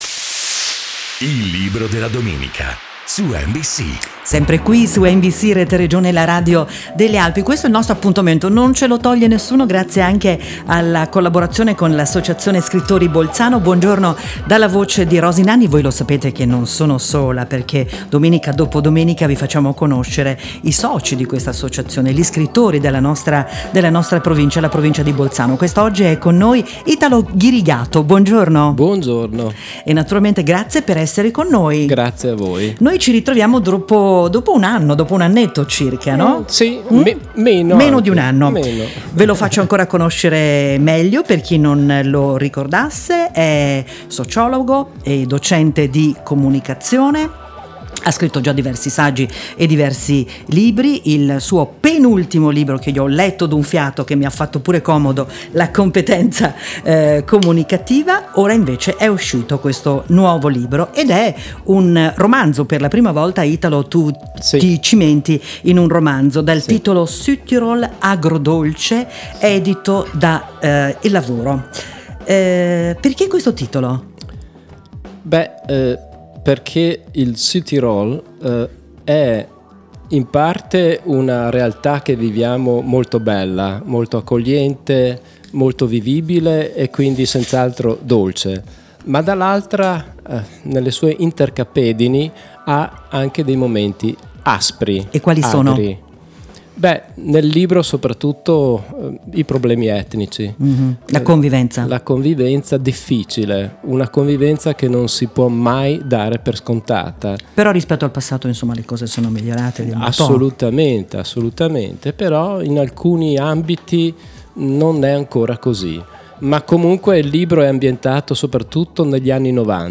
Intervista su radio nbc “Il Libro della Domenica” del 2 Dicembre 2018